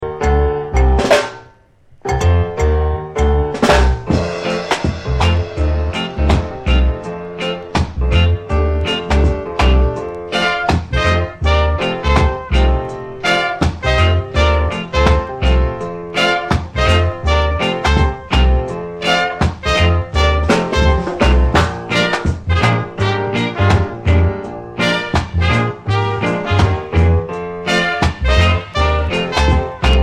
Reggae Ska Dancehall Roots Vinyl Schallplatten ...